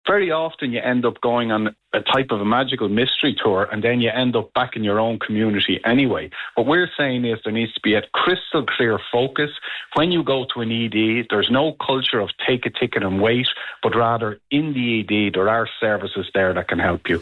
spoke to Kildare Today this morning about the reform needed in our Accident and Emergency Services for Mental Health patients: